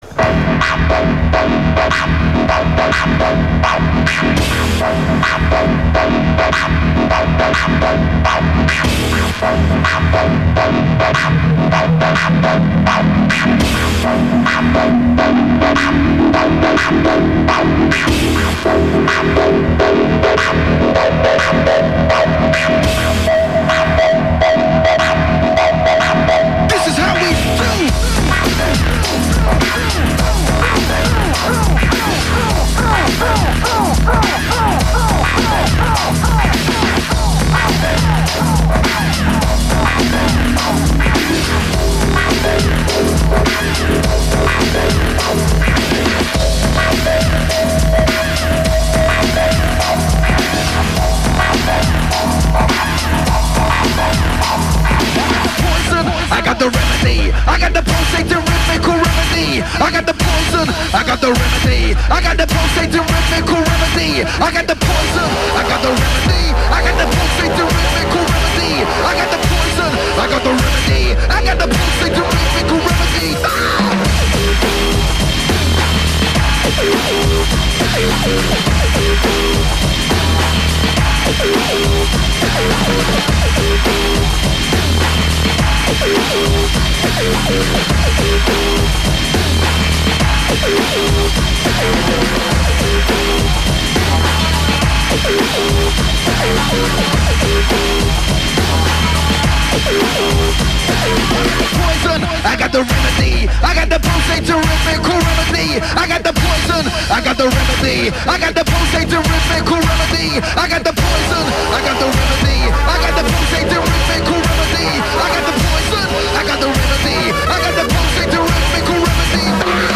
Completely reworked the classics into something crazy!